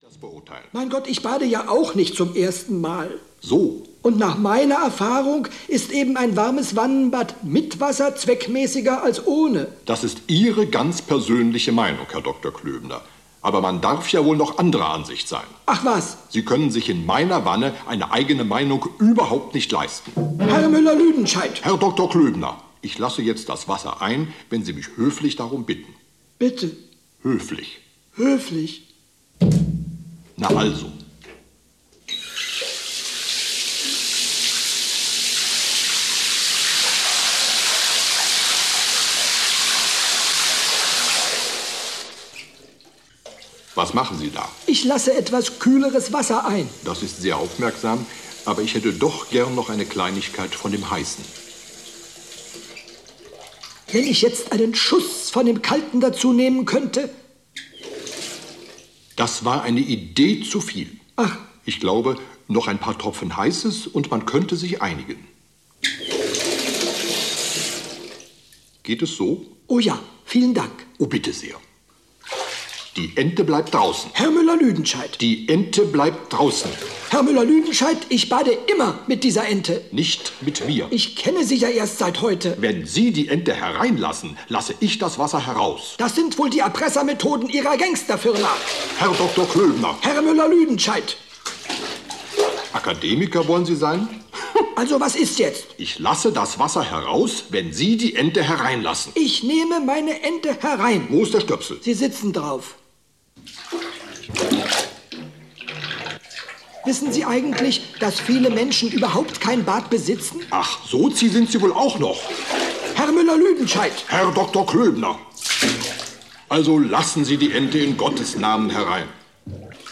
Meisterwerke - Loriot - Hörbuch